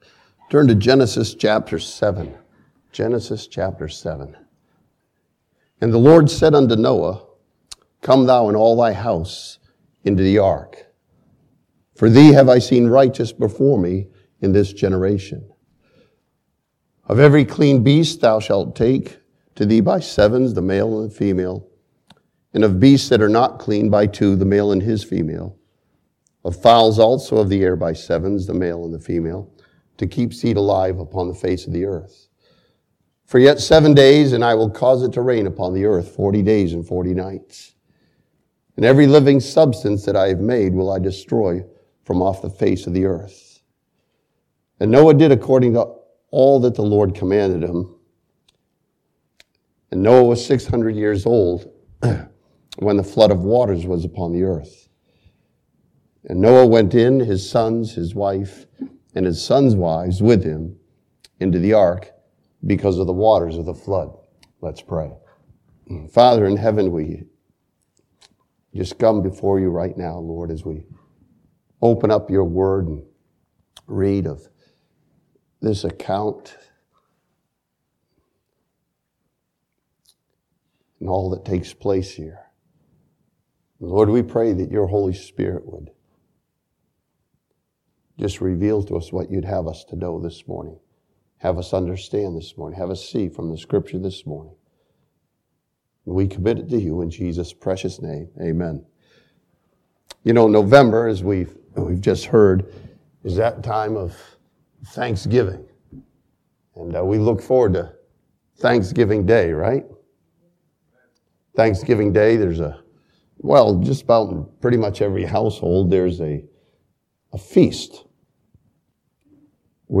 This sermon from Genesis chapter 7 studies the grace of God that was shown to Noah and his family.